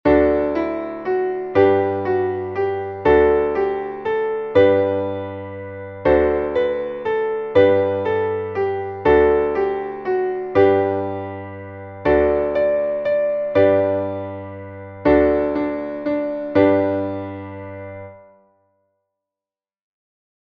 Traditioneller Kanon